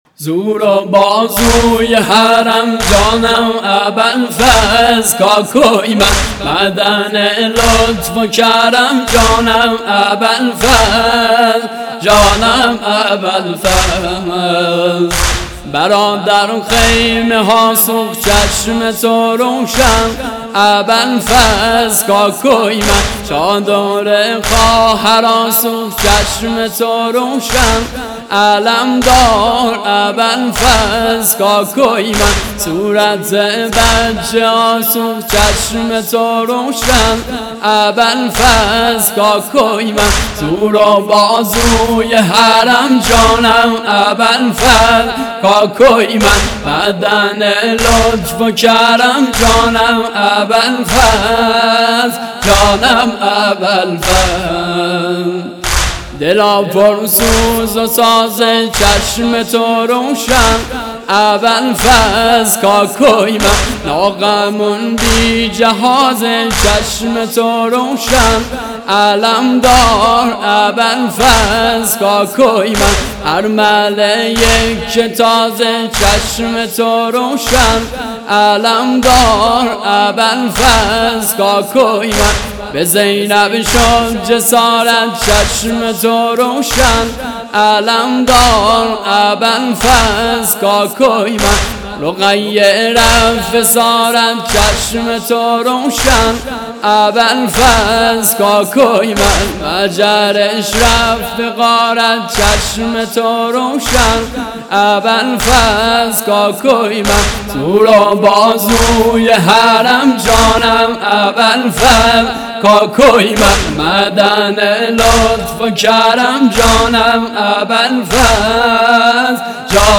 نوحه محرم 97